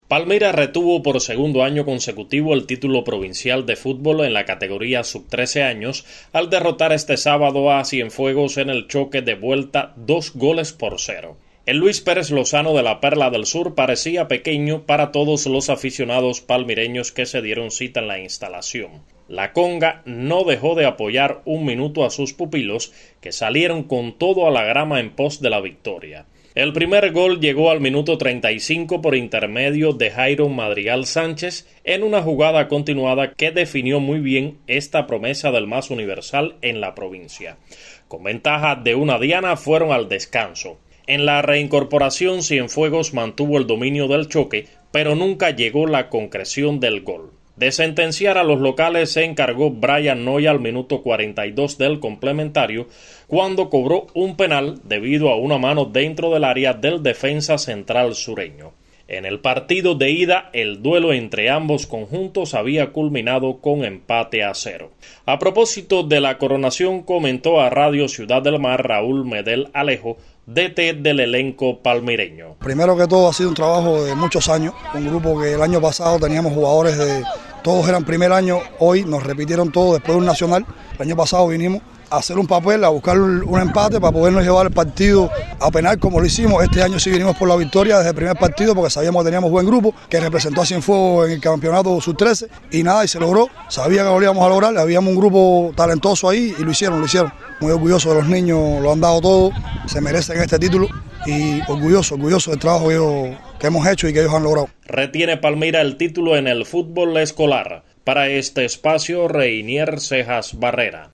Escuche el reporte del periodista